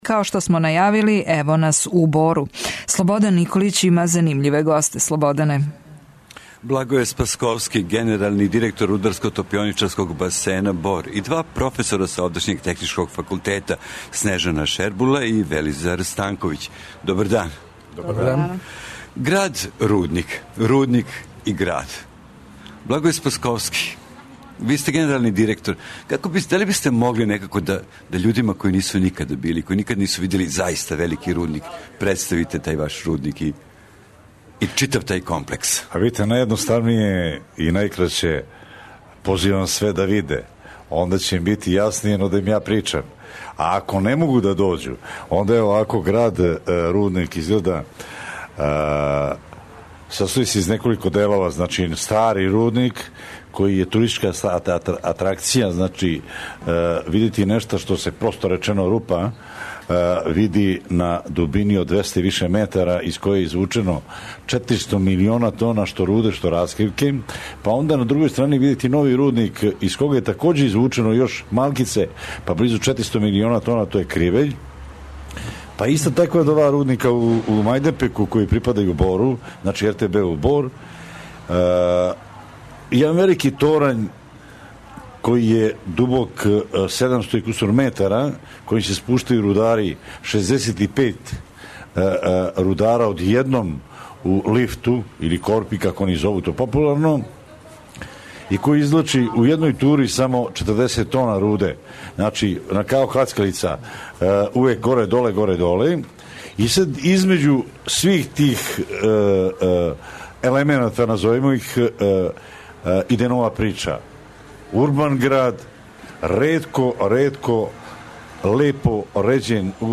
Емисију реализујемо из два студија: сталног, београдског и привременог, у Бору.